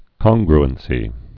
(kŏnggr-ən-sē, kən-gr-)